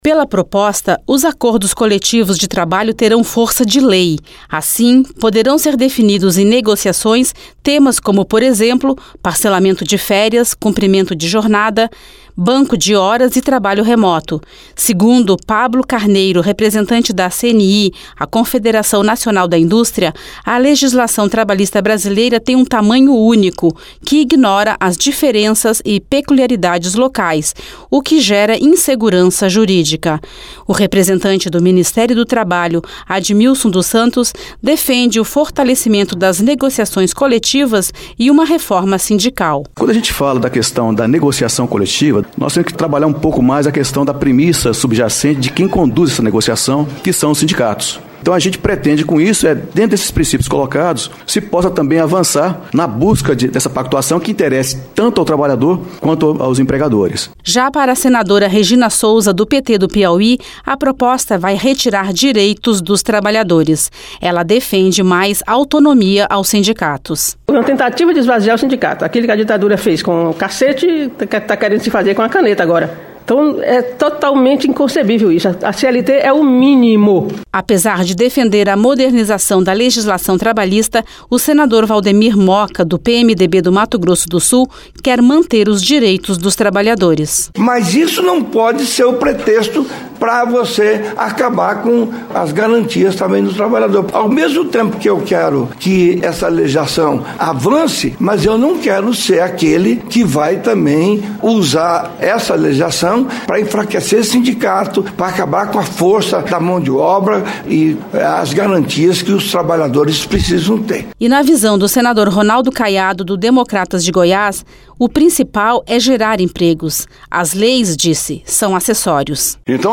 Reportagem